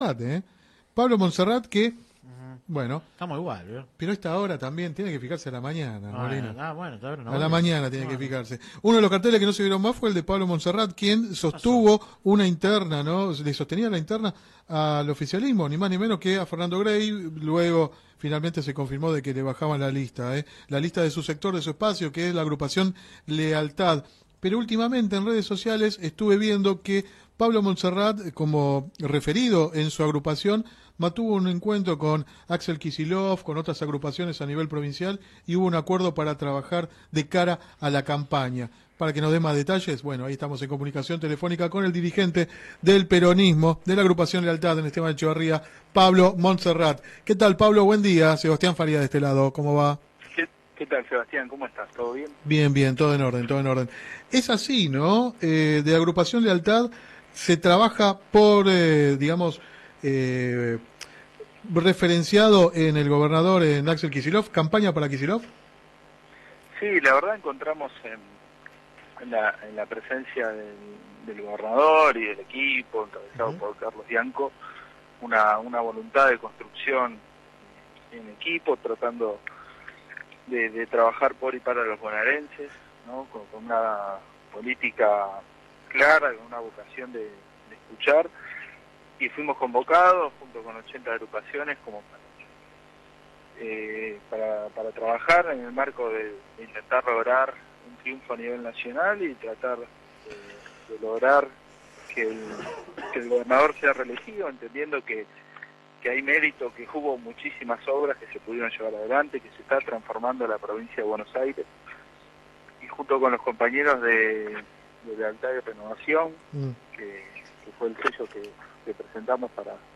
Click acá entrevista radial https